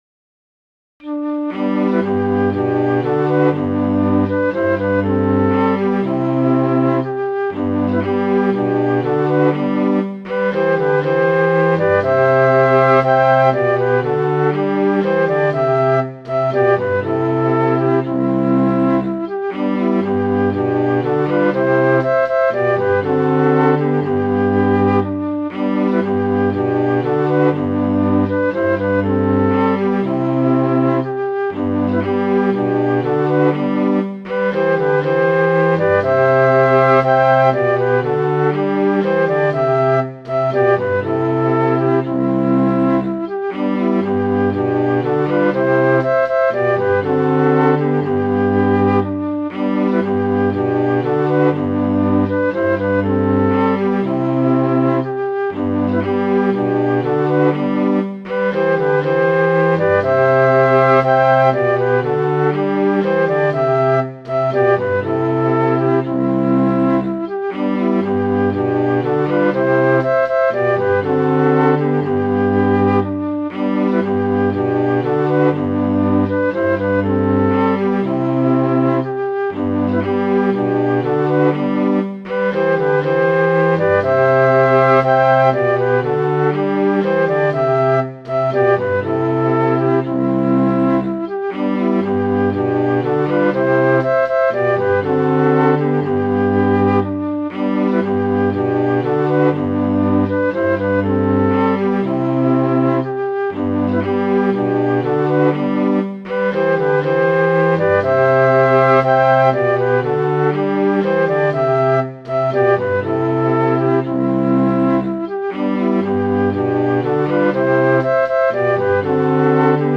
Midi File, Lyrics and Information to The Battle of Otterburn